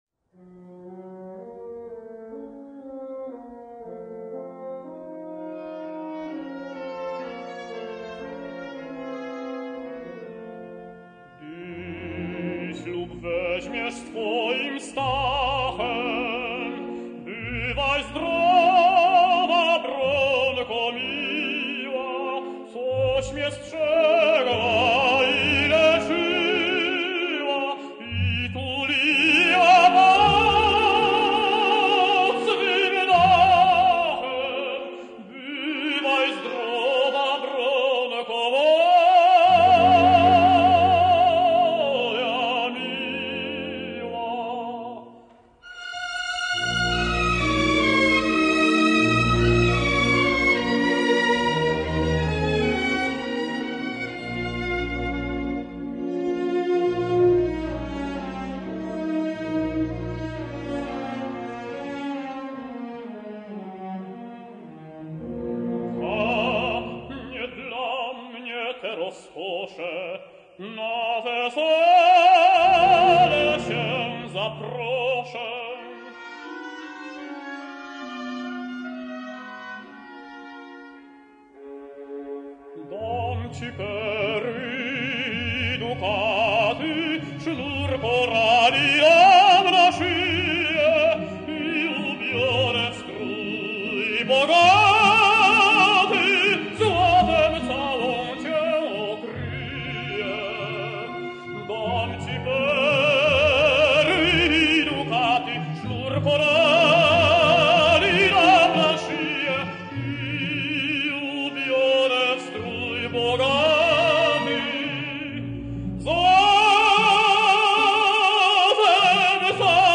Bogdan Paprocki singsJanek: